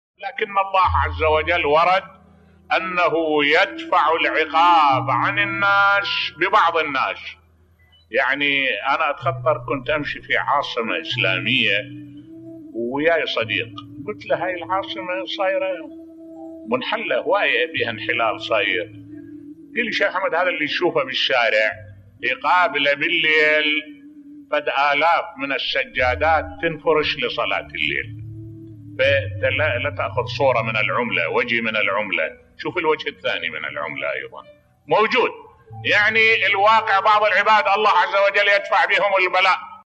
ملف صوتی أن الله يدفع العقاب عن الناس ببعض الناس بصوت الشيخ الدكتور أحمد الوائلي